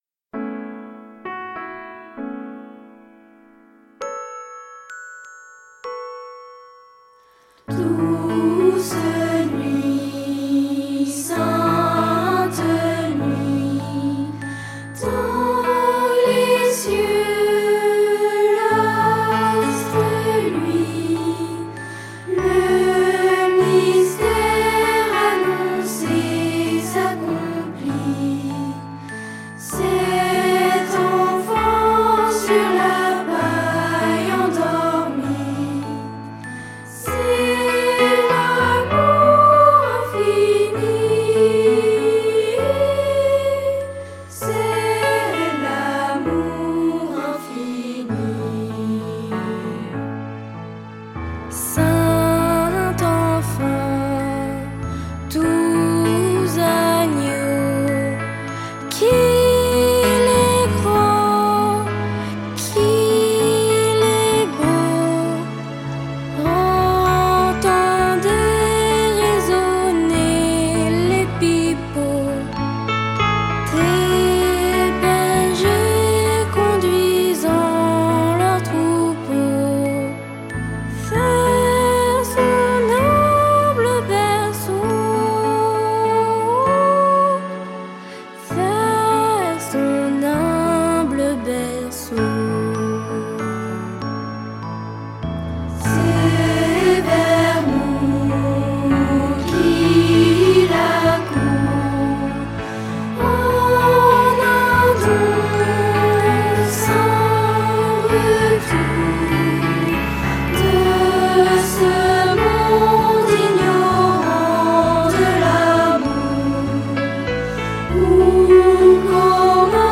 Ce livre, délicatement illustré, présente avec tendresse des chants traditionnels, disponibles sur CD-Rom et sur notre site Internet.